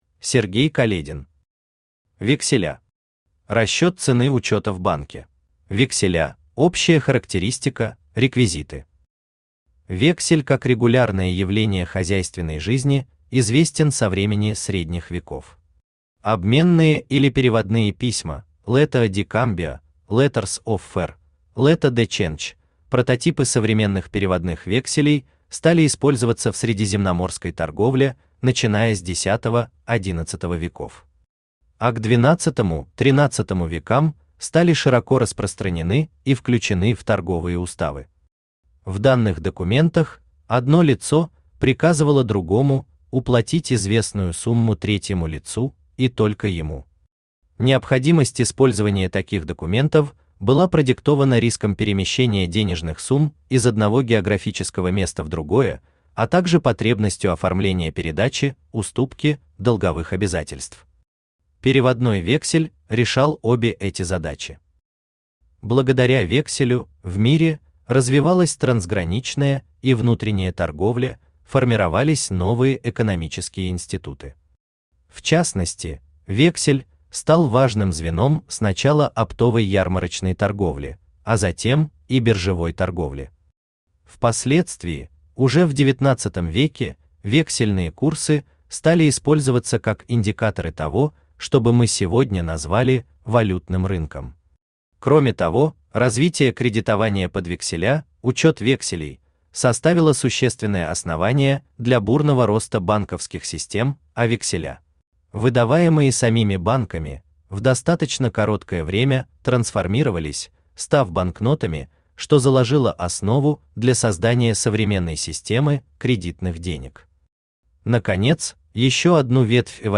Аудиокнига Векселя. Расчёт цены учёта в банке | Библиотека аудиокниг